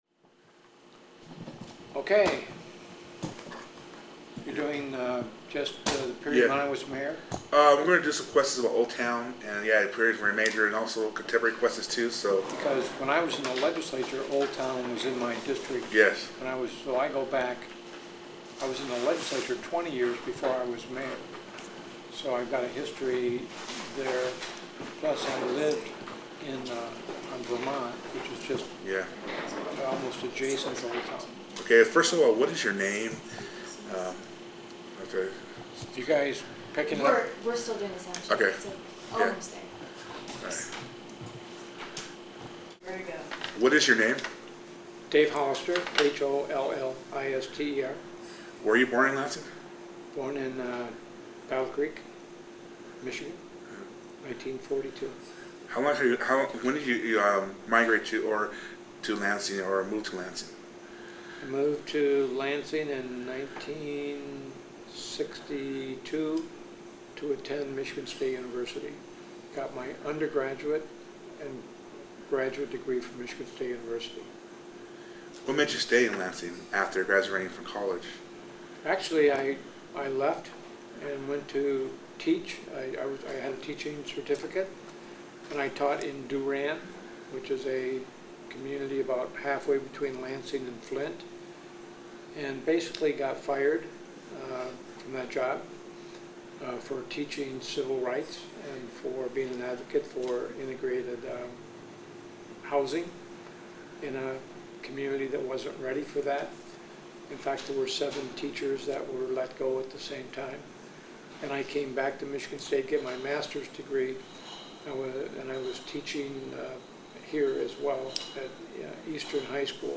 Interview of former Lansing Mayor David Hollister